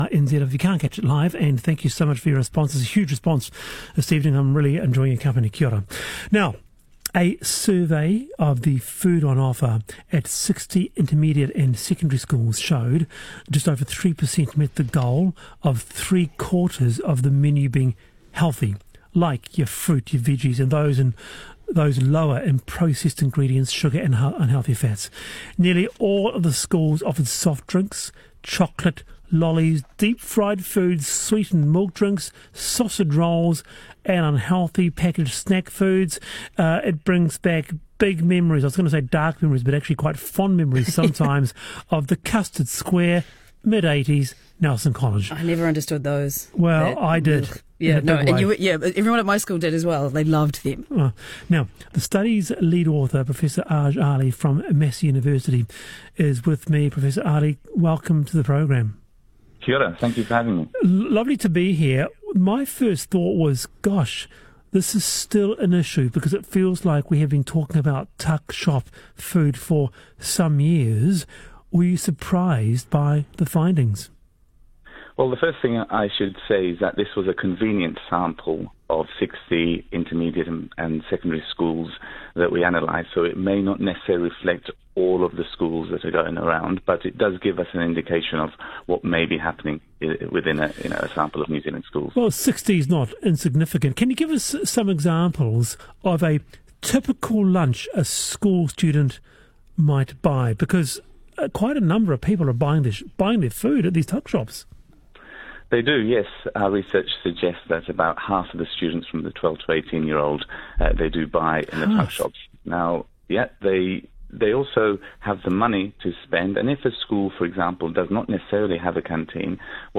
Interview with RNZ